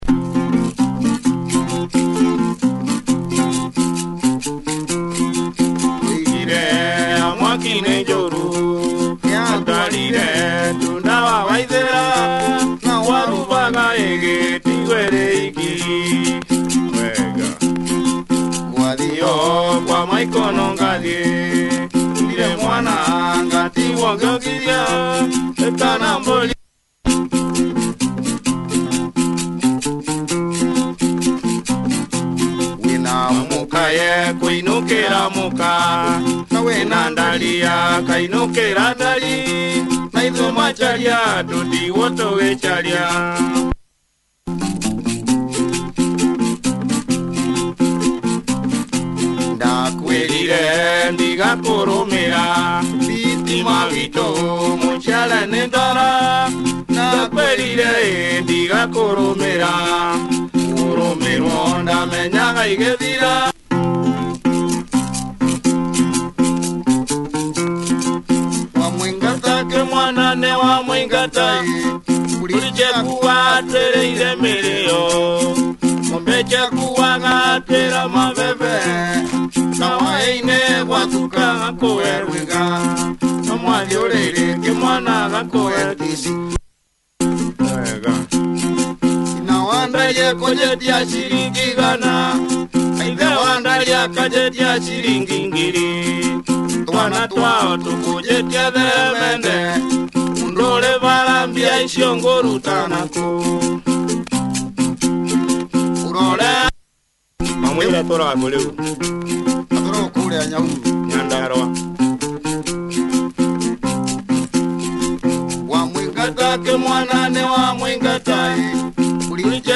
Traditional Kikuyu guitar / vocal / percussion razzle.